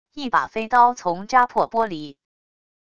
一把飞刀从扎破玻璃wav音频